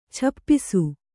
♪ chappisu